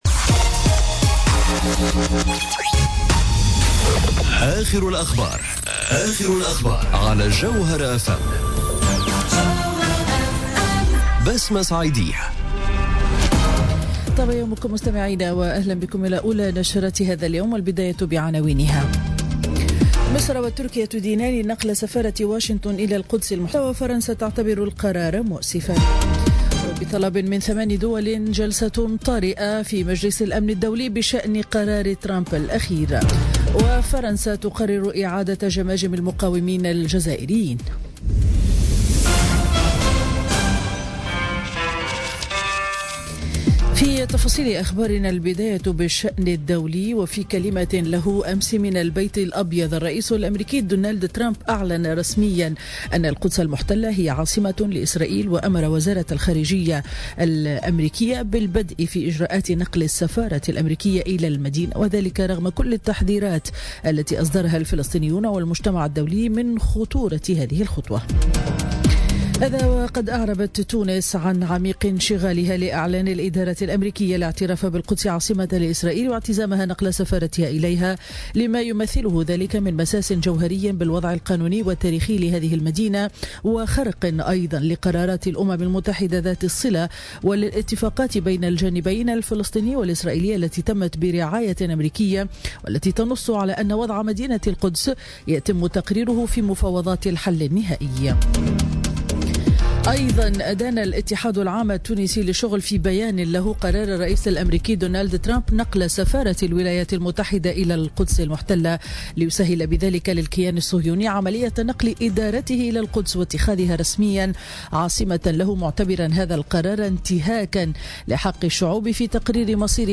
نشرة أخبار السابعة صباحا ليوم الخميس 7 ديسمبر 2017